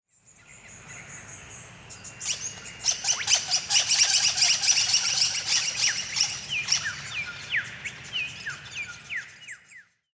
• Often described as “liquid” or “tinkling” notes
Crimson Rosella vs King Parrot Calls: Hear the Difference
If king parrot calls are clear announcements (“I’m here!”), crimson rosella sounds are quiet conversations (“Are you there?”).
Press play below to hear the rosella’s soft, musical chatter, then compare it to the king parrot’s bold whistle in our sound library.